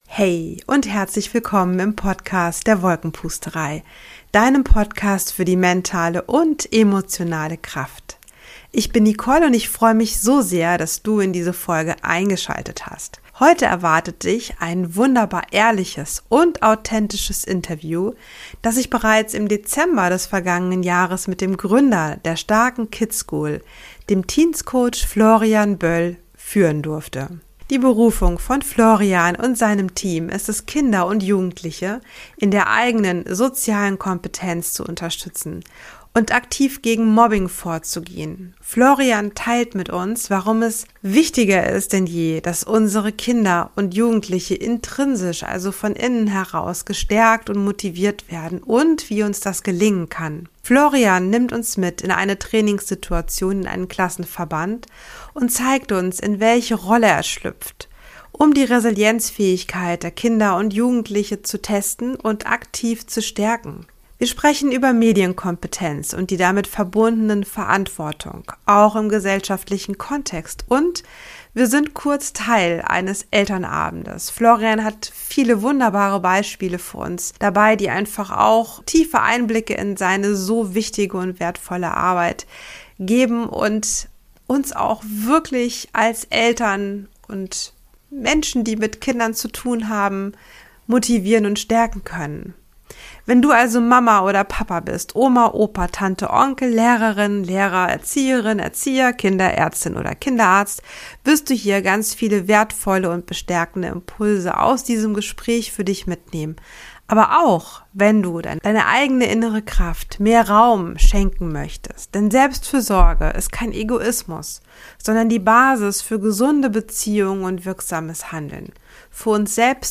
#63 Sei milde mit dir selbst und anderen - Interview